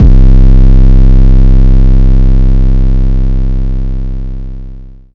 DEEDOTWILL 808 37.wav